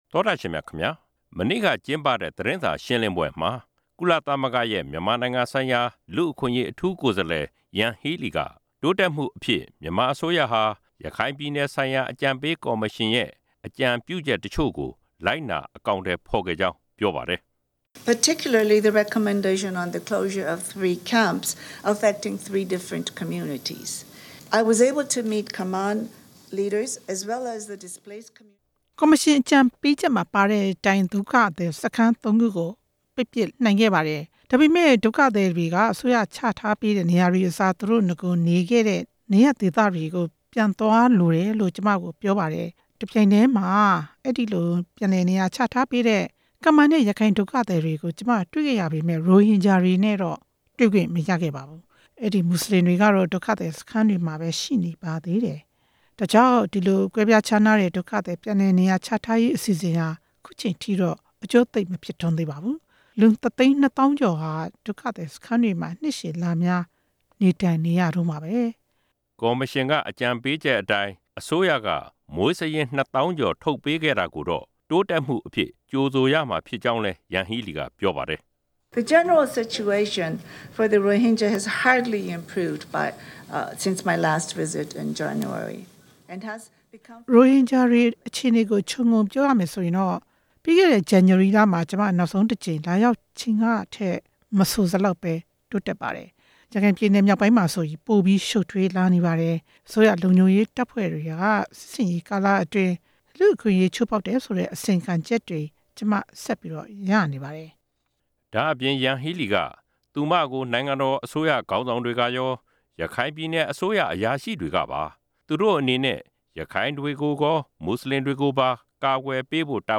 ရန်ကုန်မြို့မှာ မနေ့က ကျင်းပတဲ့ သတင်းစာရှင်းလင်းပွဲမှာ